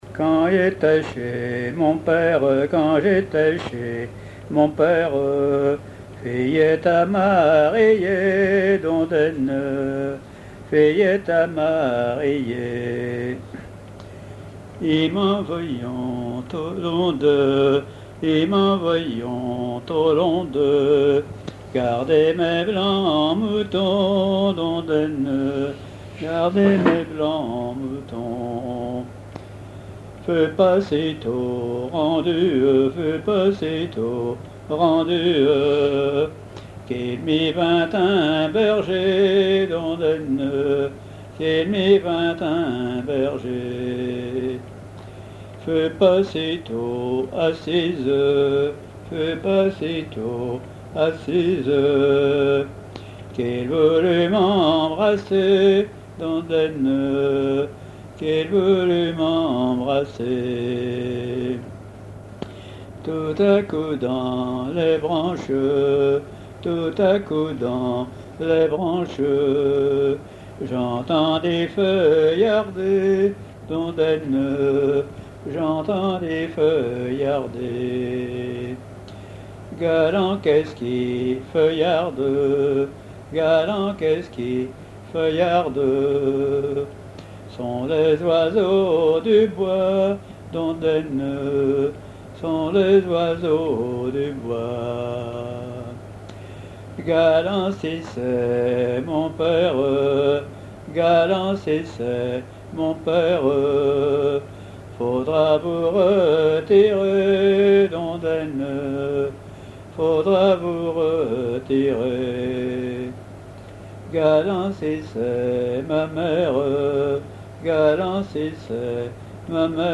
Genre laisse
chansons à ripouner ou à répondre
Pièce musicale inédite